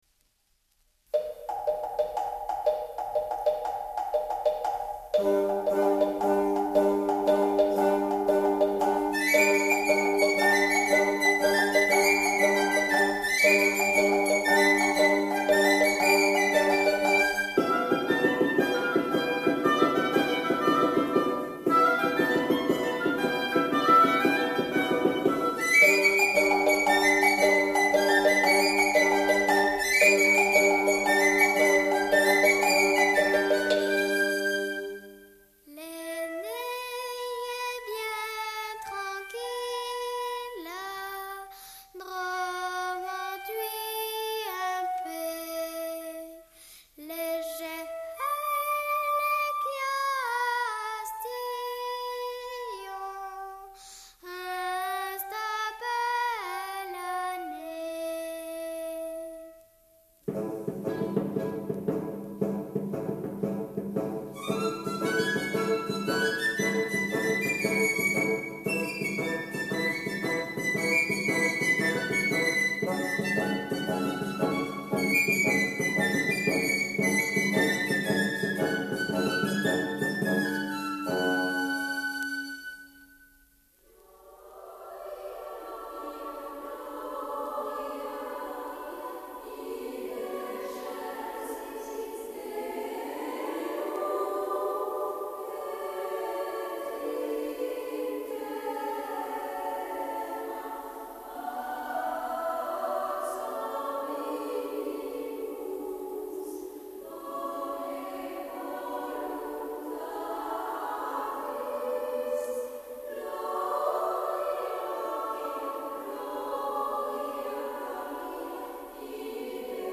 The work blends the trained singing